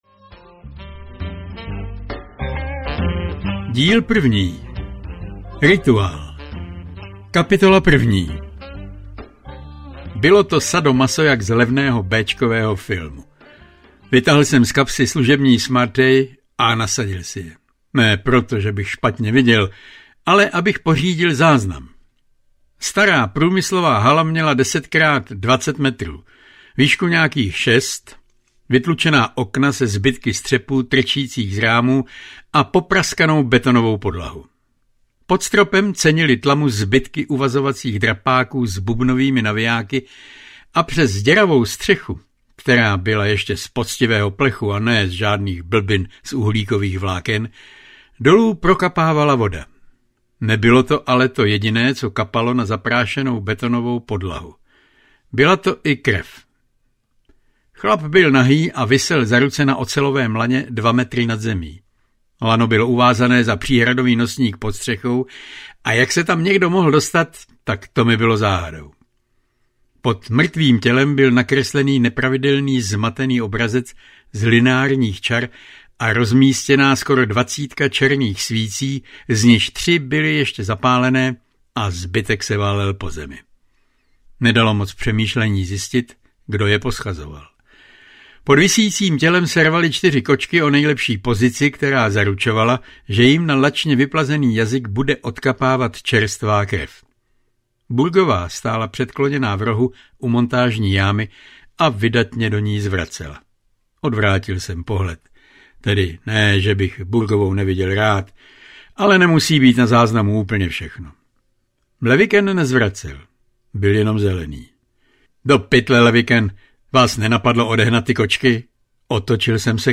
Nezkoušej na mě zapomenout audiokniha
Ukázka z knihy